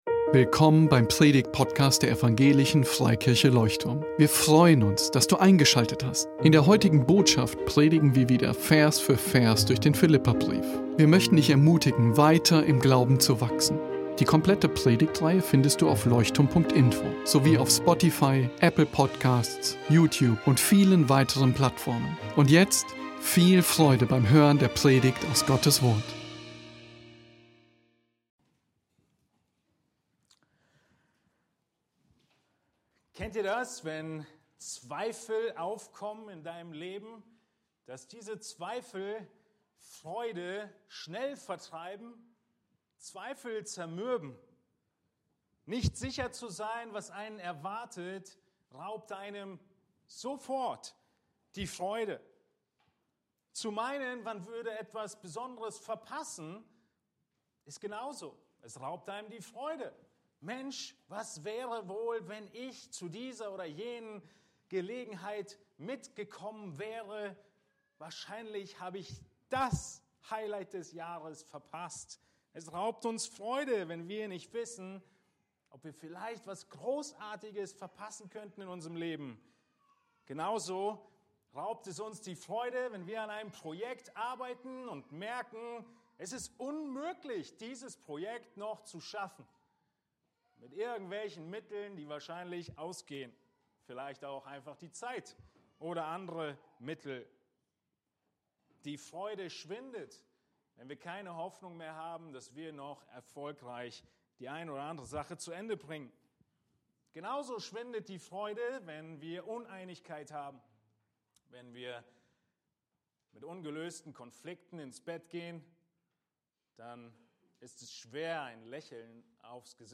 Leuchtturm Predigtpodcast Podcast